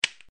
switch.wav